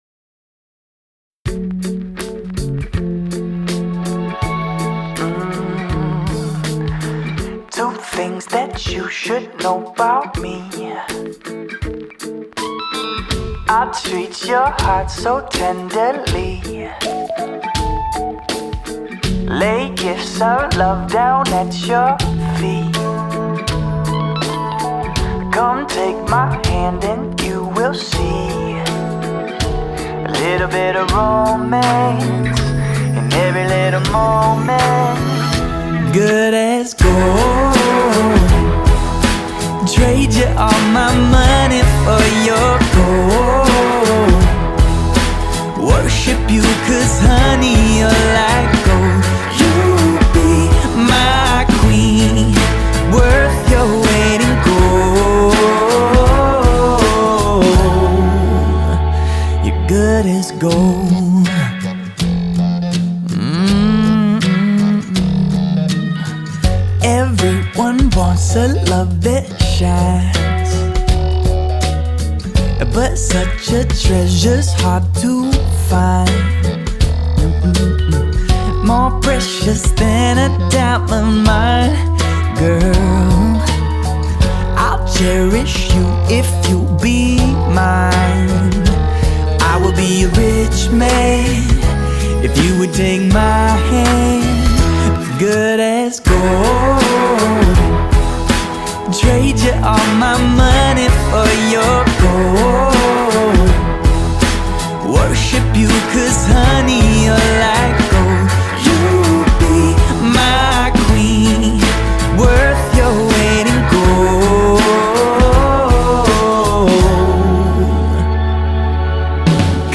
mutlu huzurlu rahatlatıcı şarkı.